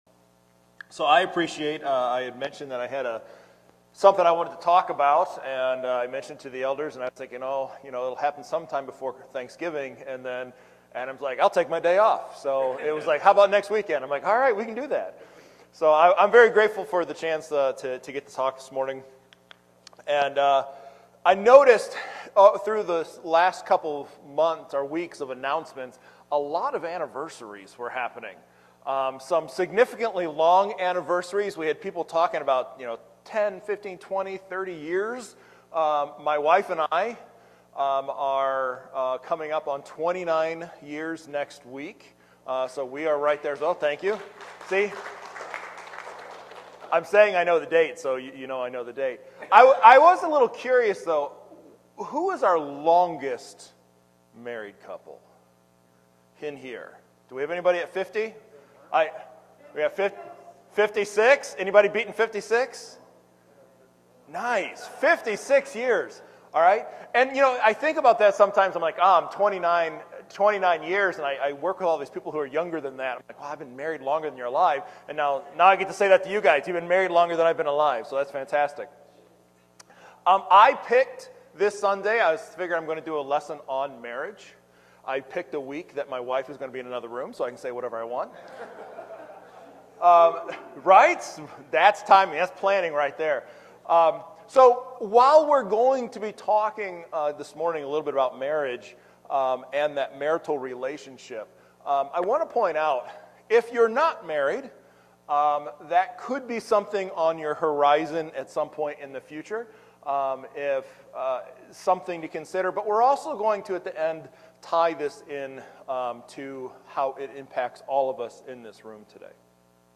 Sermons | Wheeler Road Church of Christ